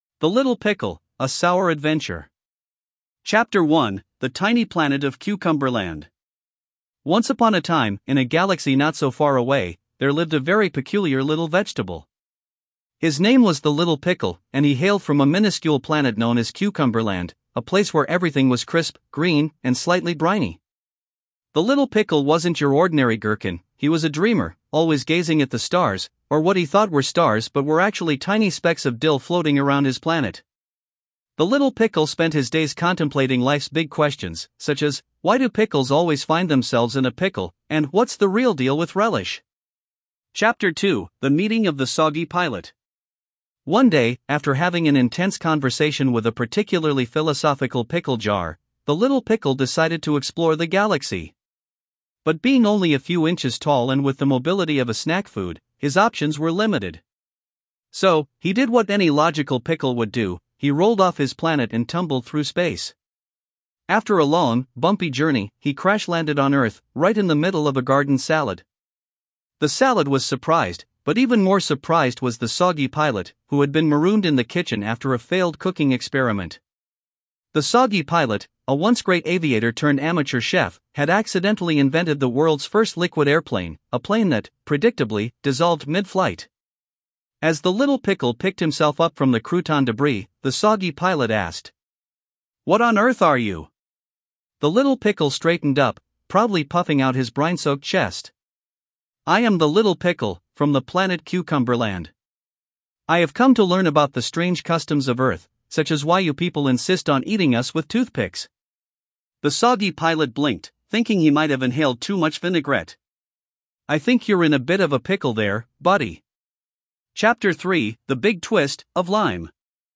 More Audio Books